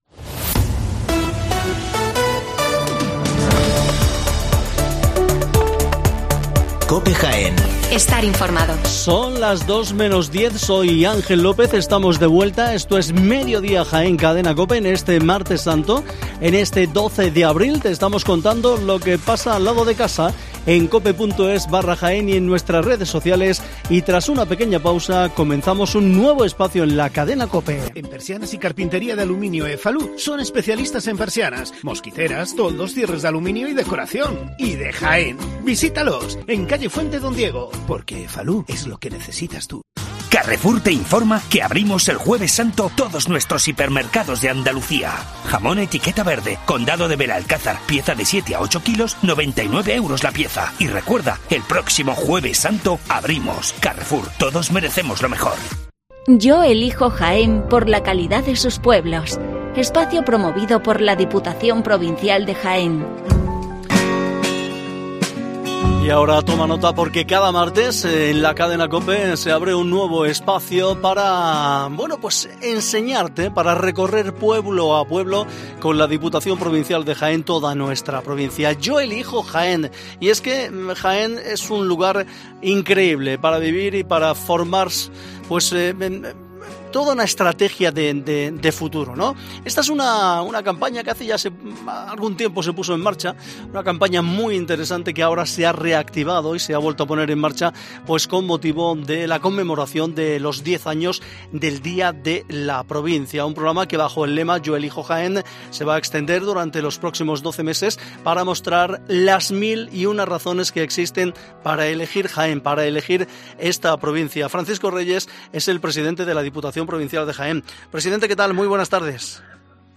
Entrevista con el presidente de la Diputación de Jaén, Francisco Reyes